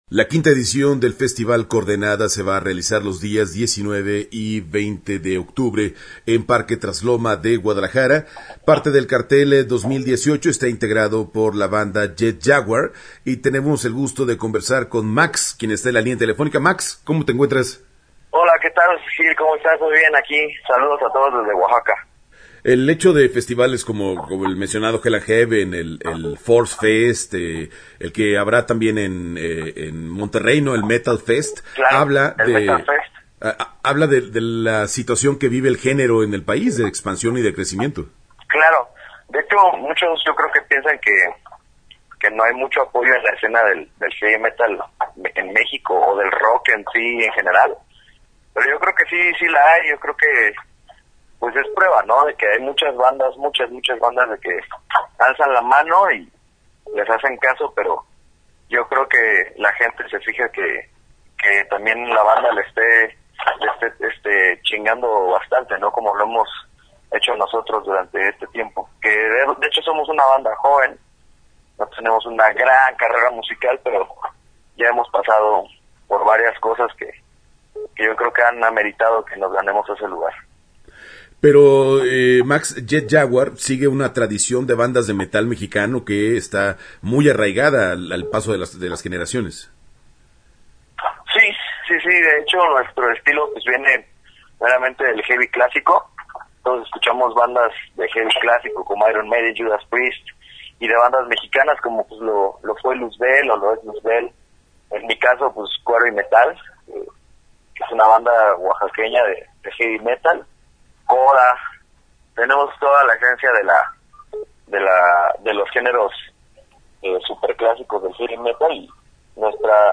Entrevista-Jet-Jaguar-Coordenada-2018-web.mp3